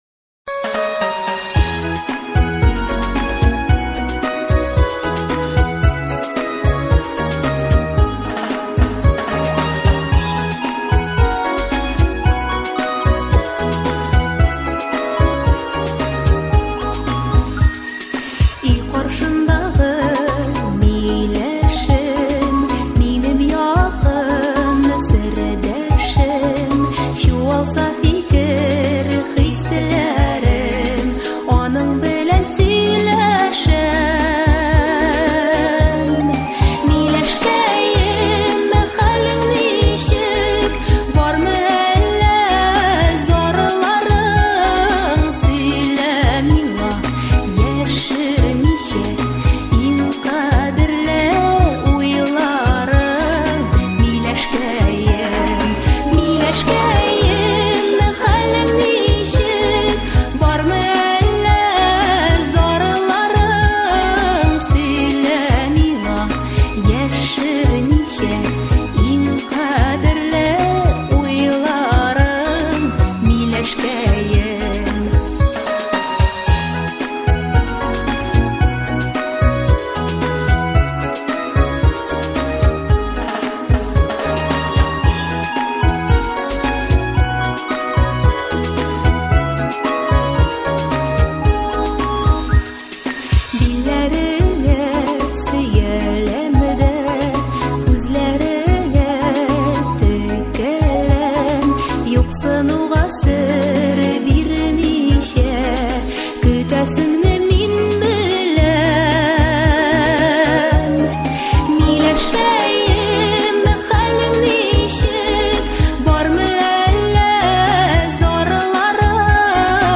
• Категория: Детские песни
татарские детские песни